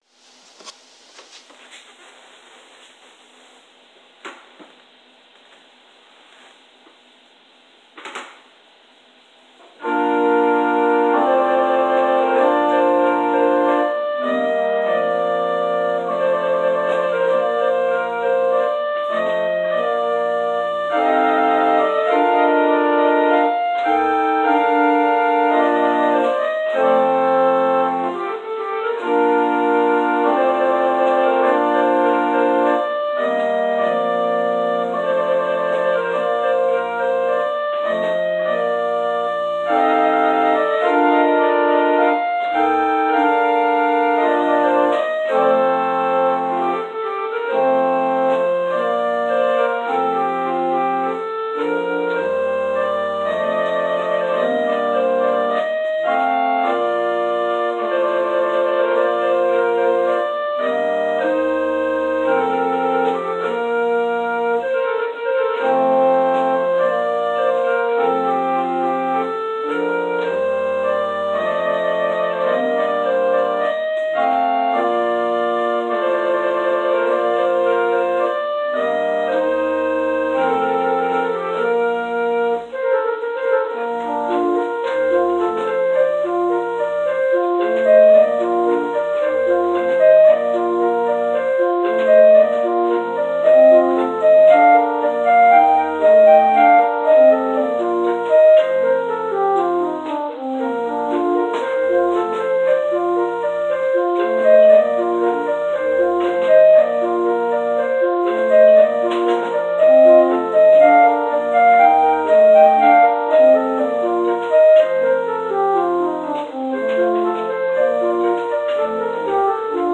Prelude: “Air and Variations” – G. F. Handel